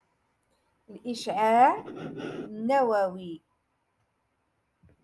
Moroccan Dialect- Rotation Six - Lesson Two Five